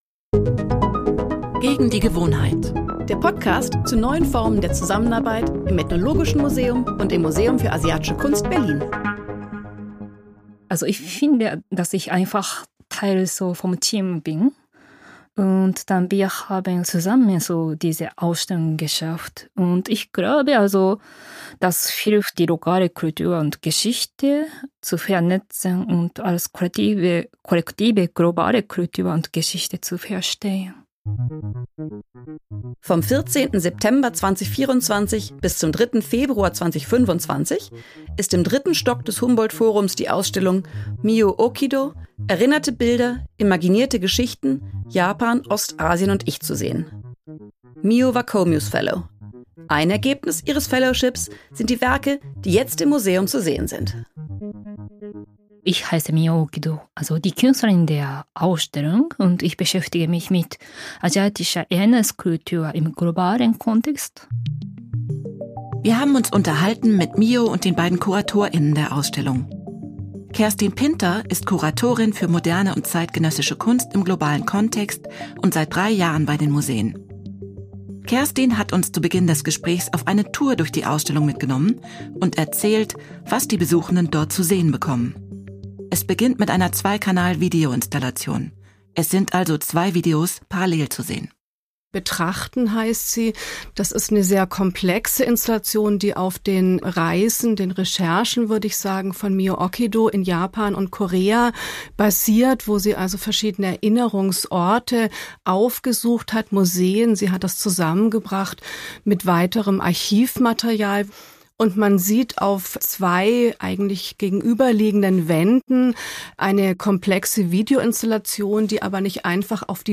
Sie sprechen mit Vertreter*innen Indigener Gesellschaften, internationalen Partner*innen und Fellows, Leuten aus der pluralen Berliner Stadtgesellschaft und den Menschen aus dem Museum, die mit ihnen kooperieren, z. B. Kurator*innen, Vermittler*innen, Restaurator*innen.